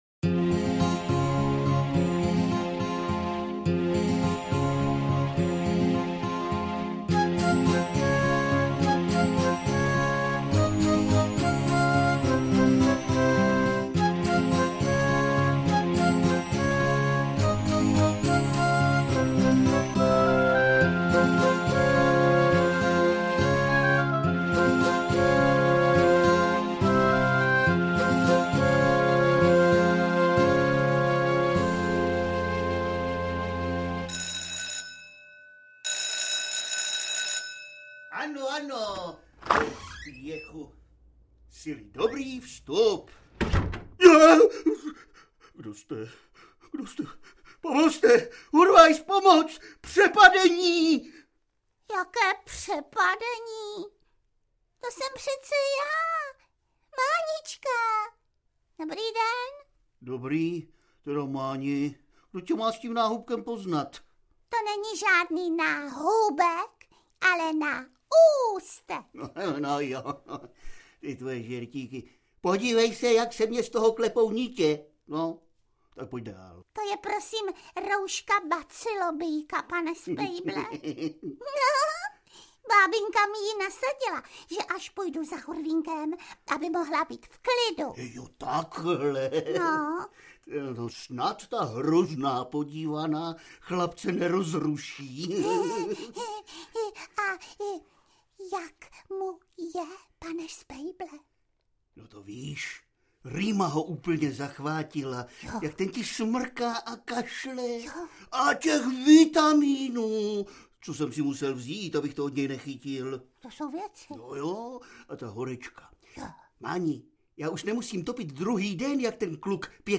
Titul je zvukovou verzí stejnojmenného představení z repertoáru Divadla S+H. A o čem je?